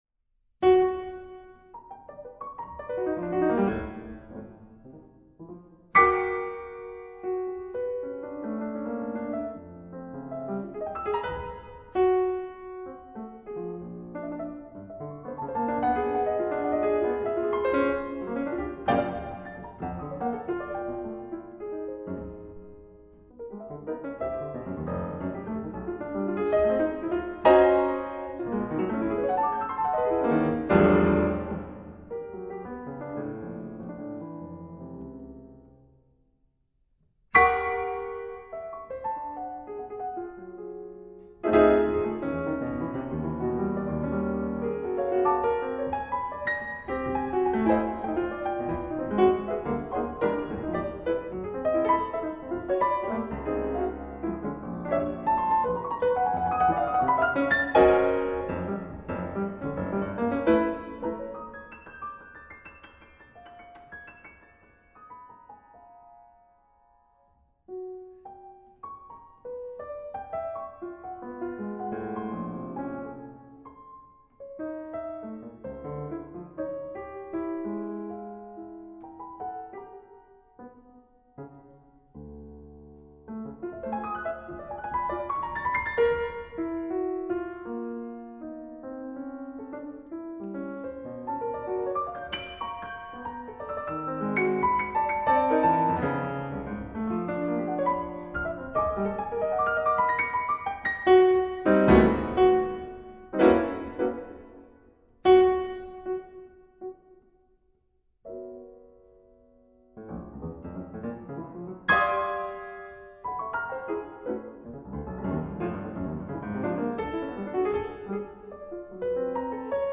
virtuosic piano solo